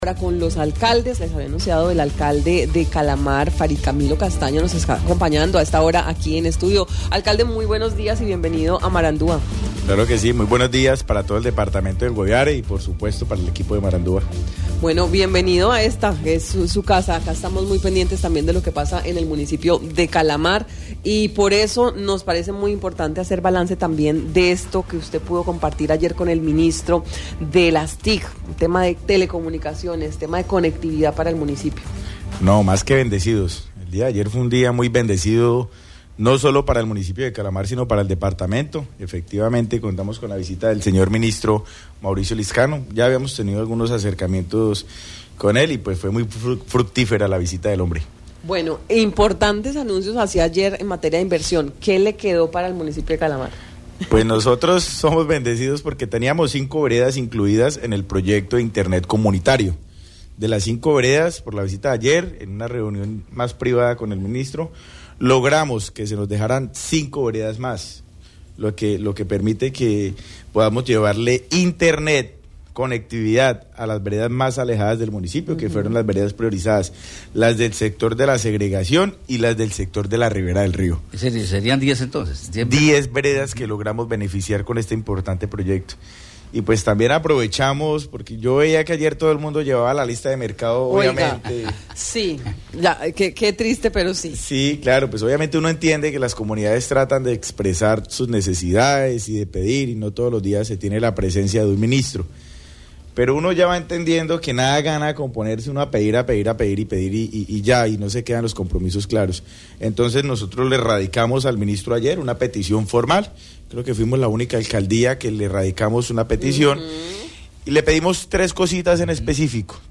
El alcalde del municipio de Calamar, Farid Camilo Castaño, en Marandua Noticias, habló sobre lo expuesto y logrado para Calamar en la Mesa de Conectividad presidida por el Ministro de las TIC, Mauricio Lizcano.